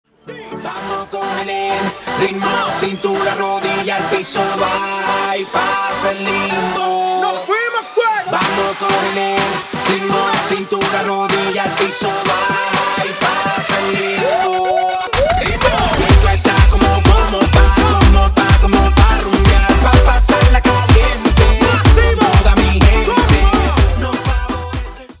• Качество: 256, Stereo
зажигательные
качает